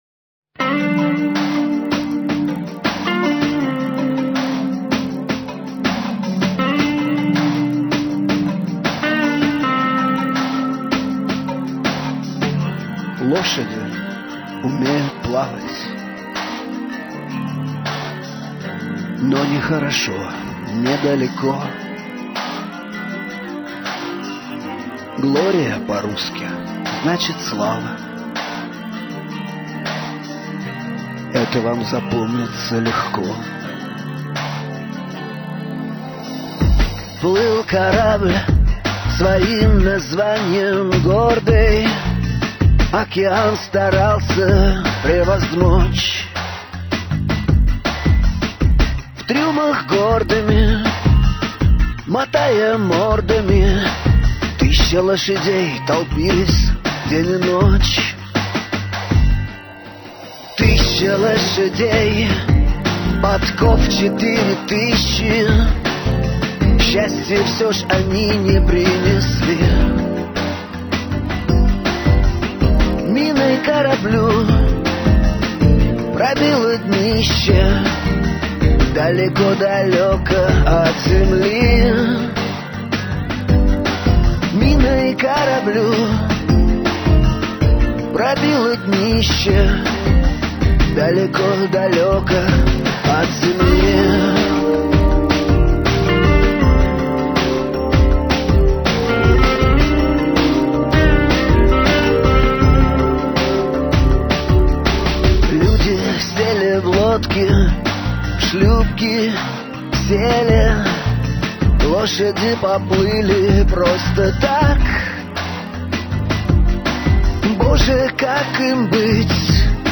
запись с песней